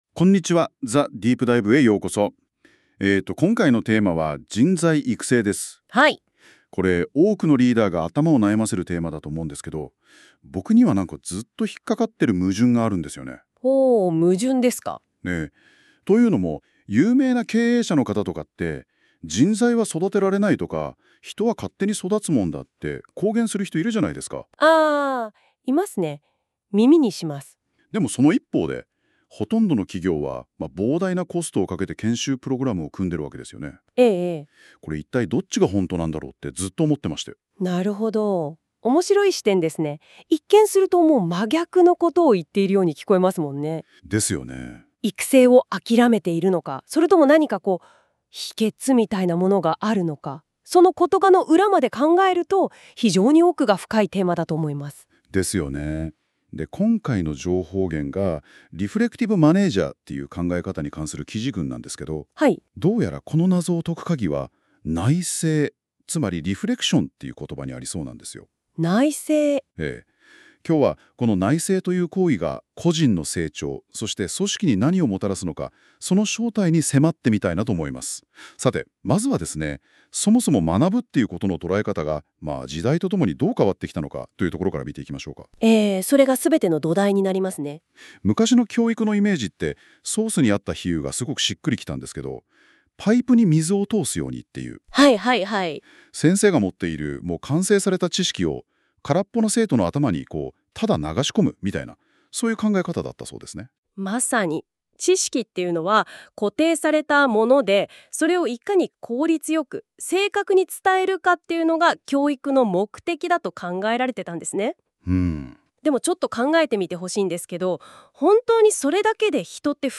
この記事の解説を対話形式の音声ファイル（mp4）で再生（15分） ↓ 経営者や管理職にとって人材の育成は取り組むべき大きな課題の一つだろう。